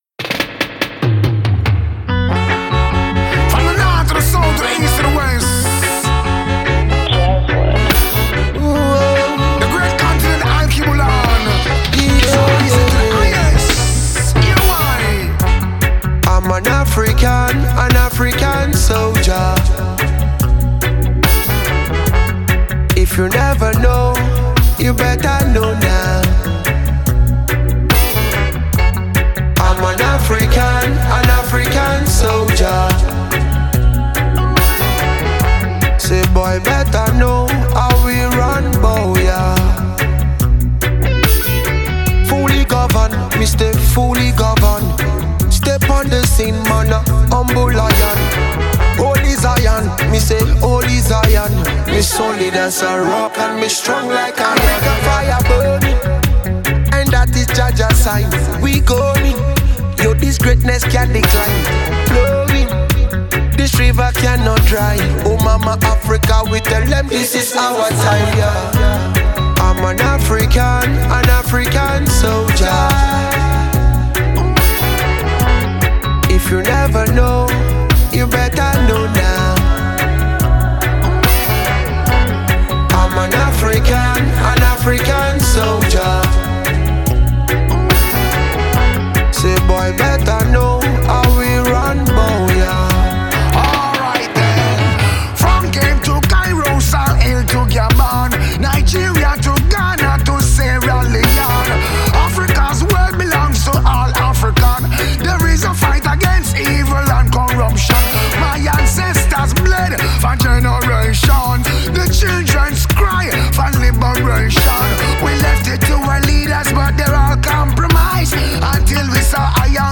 Dance hall